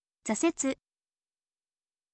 zasetsu